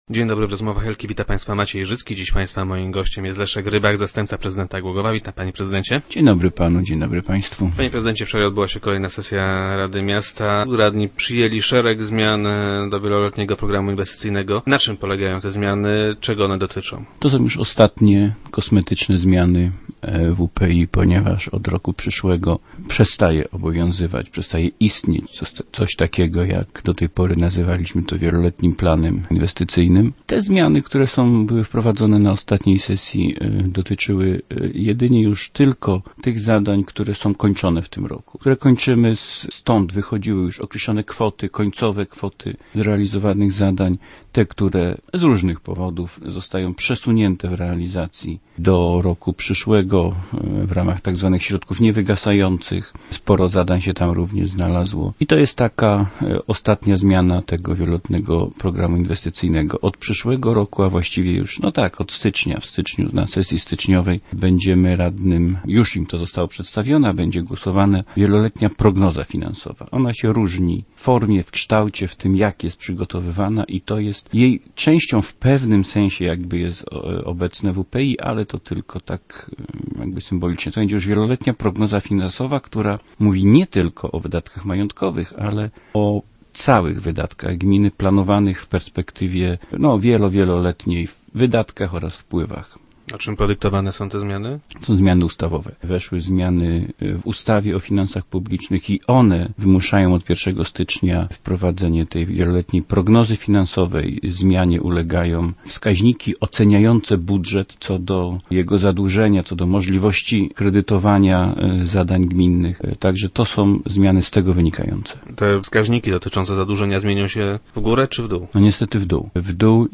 Odbije się więc to na miejskich inwestycjach - mówił na radiowej antenie wiceprezydent Rybak.